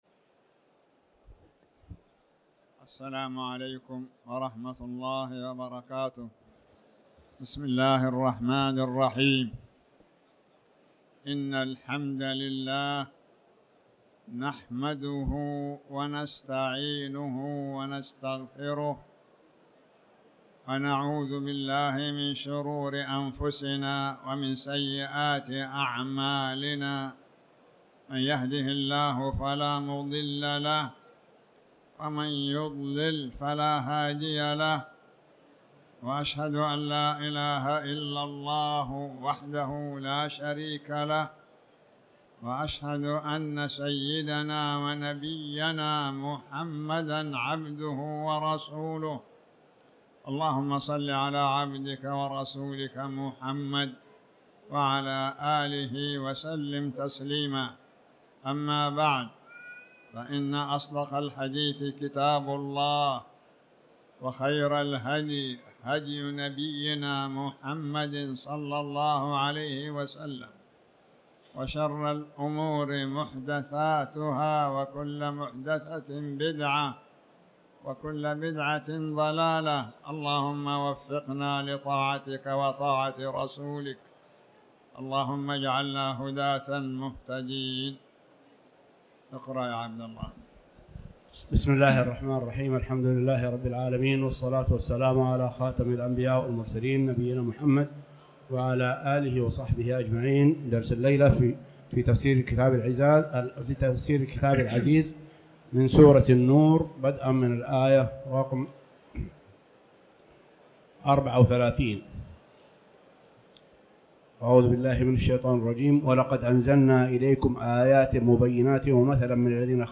تاريخ النشر ٢٤ ربيع الثاني ١٤٤٠ هـ المكان: المسجد الحرام الشيخ